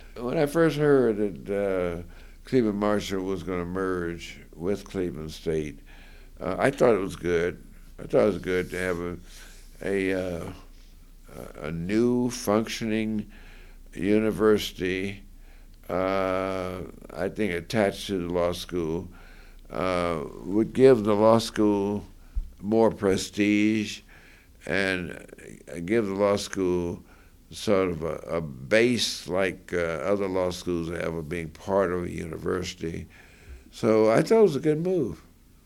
Congressman Louis Stokes relates the merger of Cleveland-Marshall College of Law with CSU.